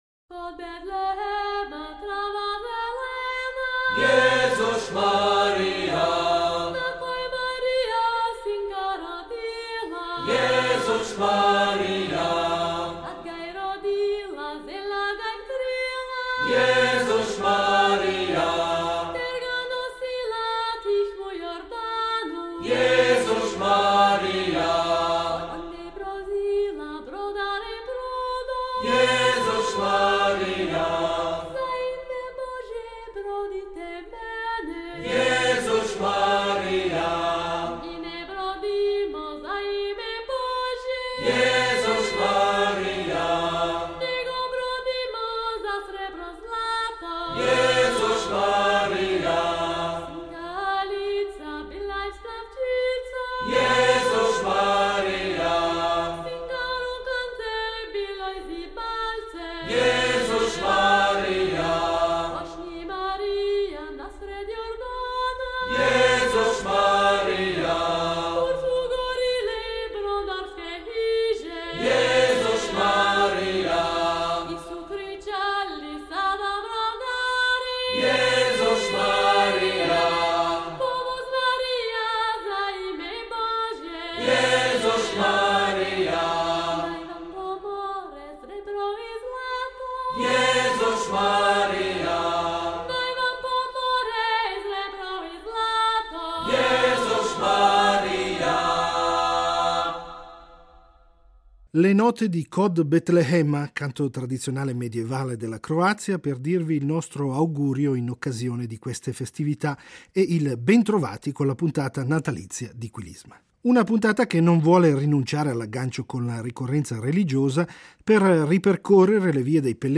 Un viaggio che Quilisma percorrerà attraverso la musica natalizia medievale di Germania, Inghilterra, Macedonia, Croazia, Bulgaria, Spagna, Turchia e Siria.